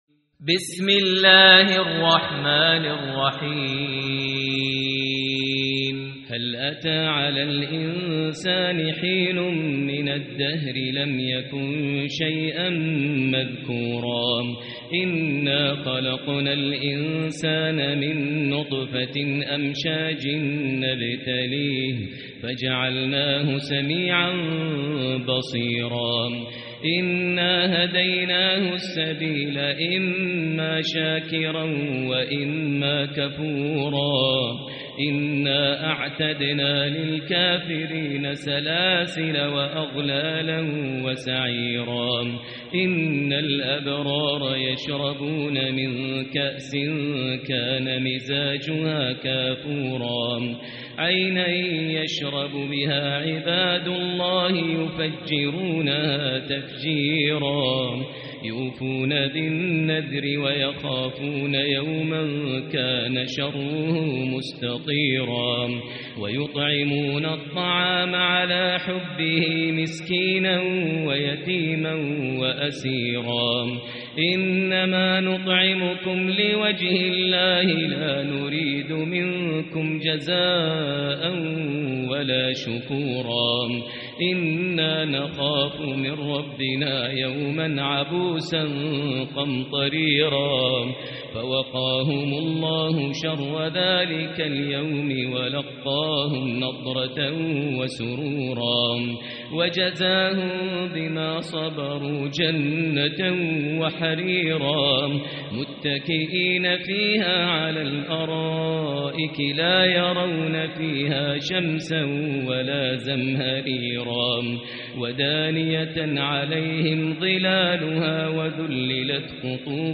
سورة الإنسان | مصحف الحرم المكي ١٤٤٤ > مصحف تراويح الحرم المكي عام 1444هـ > المصحف - تلاوات الحرمين